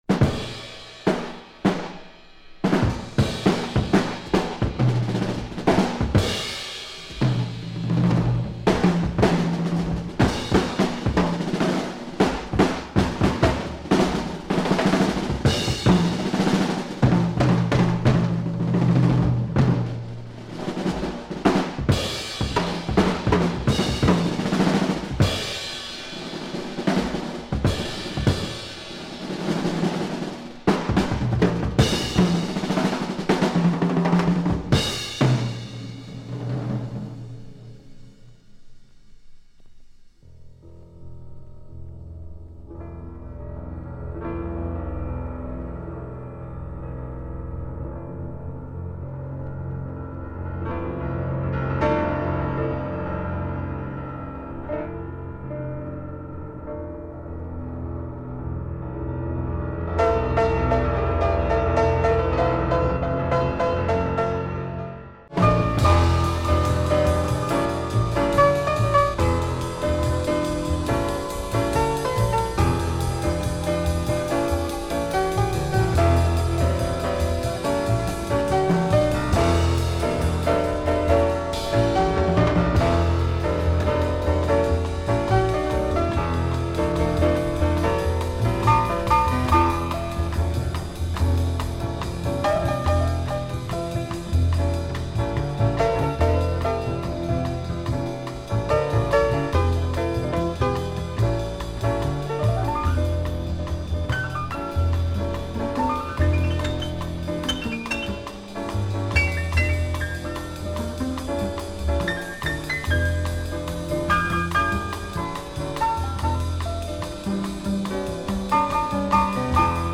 Piano led Japanese jazz by a cult artist !